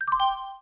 bytedesk_newmsg.wav